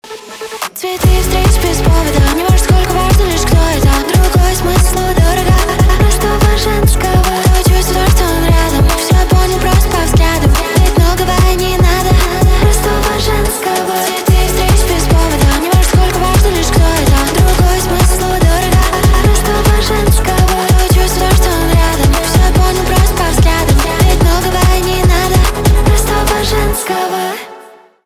поп
битовые , басы , качающие
чувственные